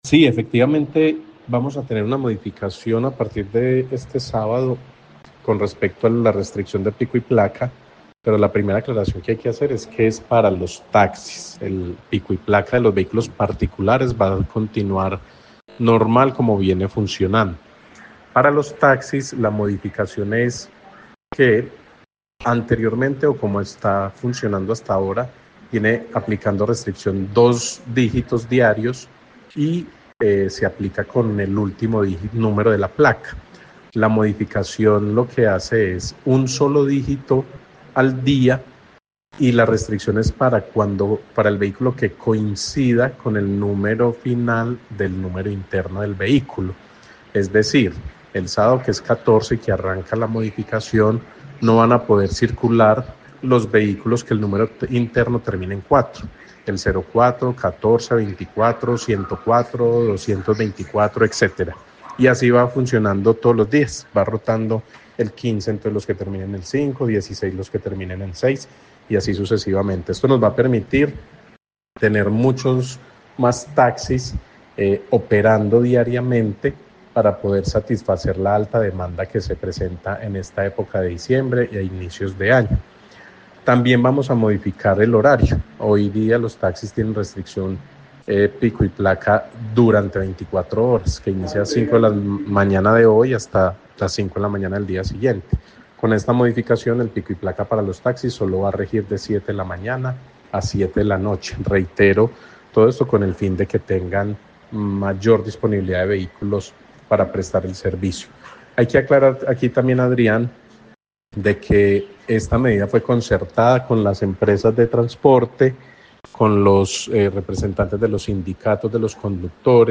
En Caracol Radio Armenia hablamos con el secretario de tránsito y transporte, Setta, Daniel Jaime Castaño que entrego detalles de los cambios de la medida de restricción vehicular para los taxis en la capital del Quindío.